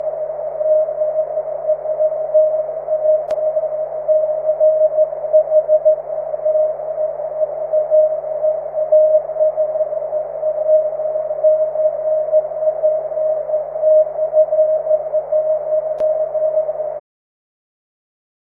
All contacts are on CW unless otherwise stated.